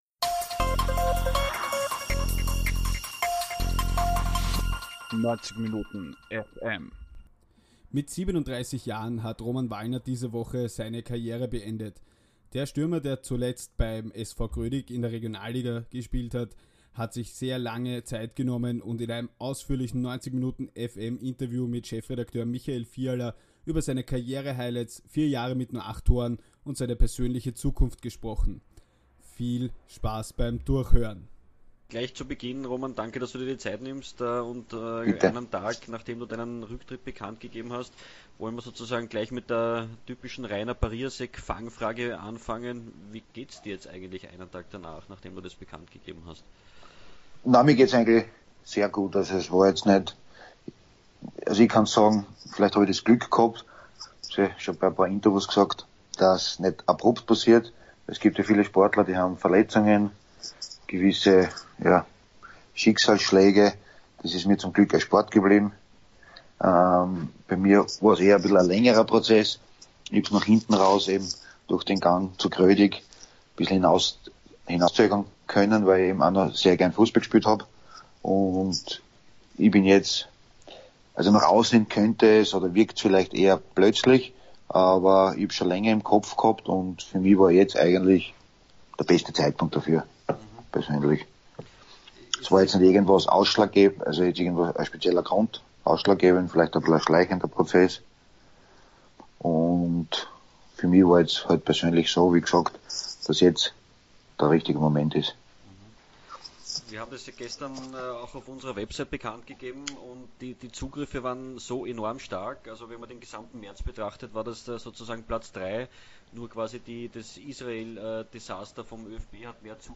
Roman Wallner spricht im ausführlichen 90minutenFM-Interview über seine Karrierehighlights, vier Jahre mit nur acht Toren und seine persönliche Zukunft.